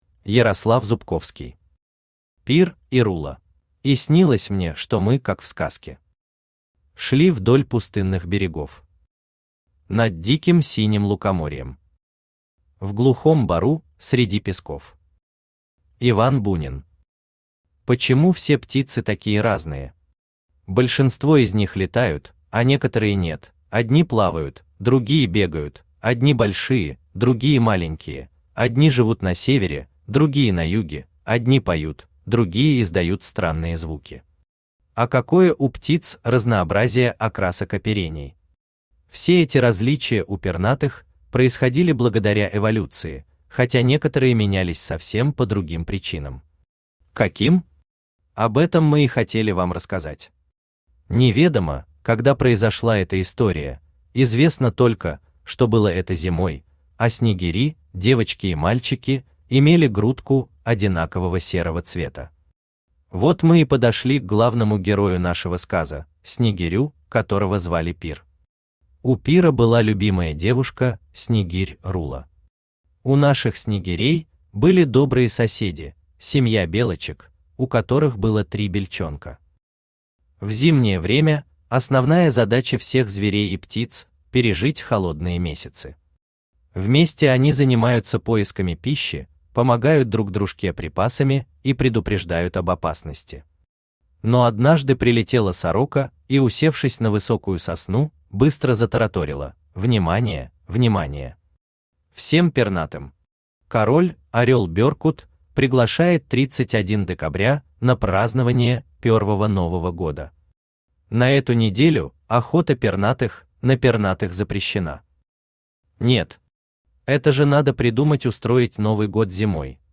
Аудиокнига Пир и Рула | Библиотека аудиокниг
Aудиокнига Пир и Рула Автор Ярослав Николаевич Зубковский Читает аудиокнигу Авточтец ЛитРес.